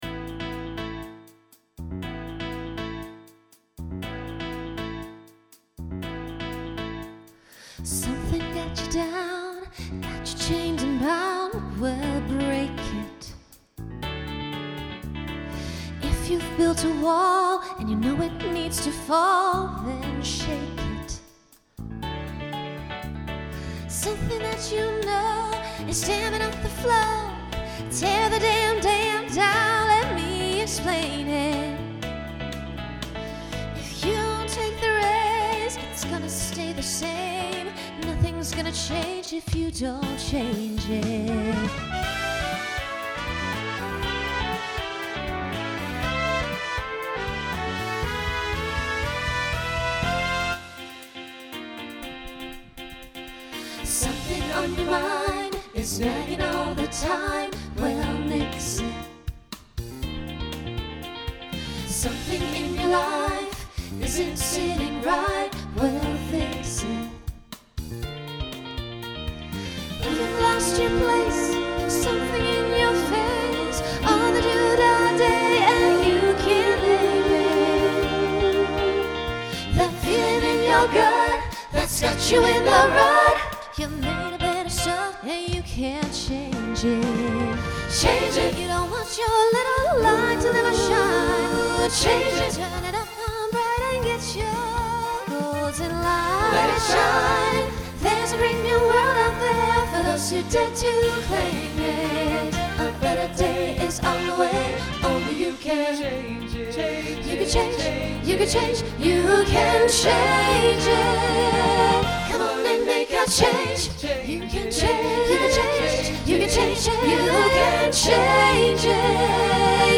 Voicing SATB Instrumental combo Genre Broadway/Film
Mid-tempo